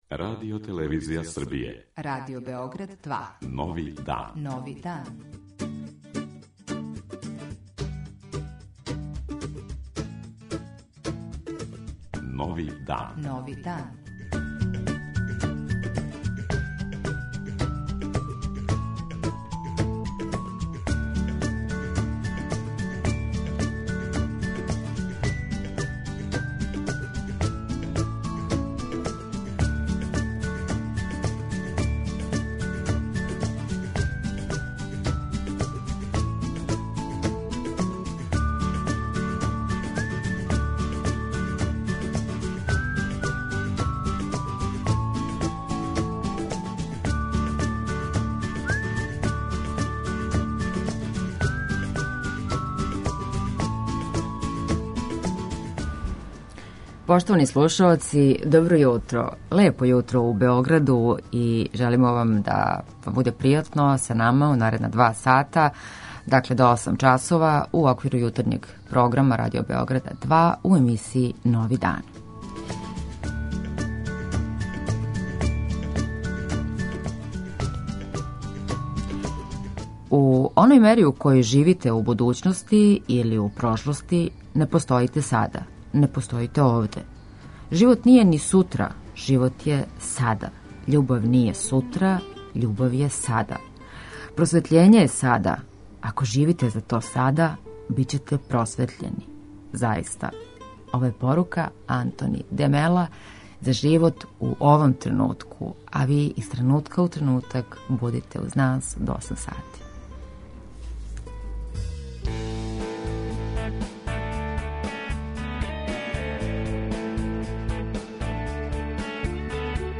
Јутарњи викенд програм магазинског типа